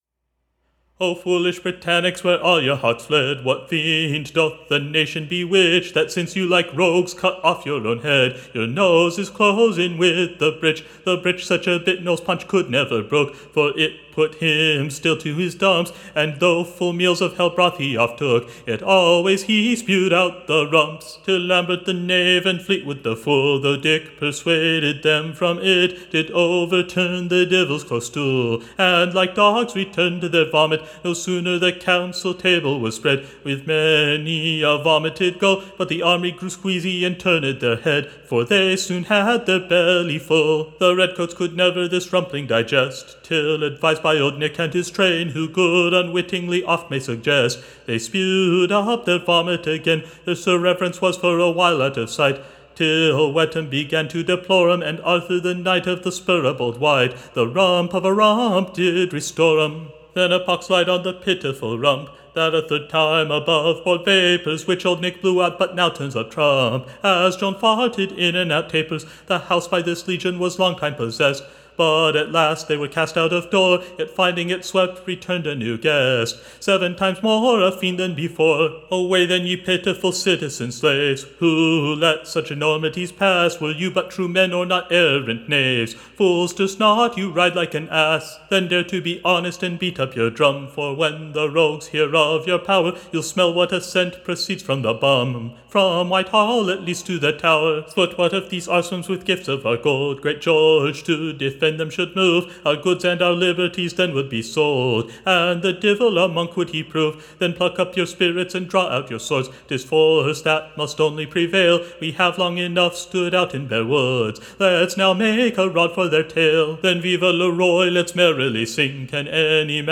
Recording Information Ballad Title A PROPER / NEW BALLAD / OF THF / Divels Arse a Peake, Or SATANS Beastly place, / Or, In plain Terms / OF the POSTERIORS and FAG-END of A Long PARLIAMENT. Tune Imprint To be said or Sung very Comfortably To the Tune of Cook Laurell.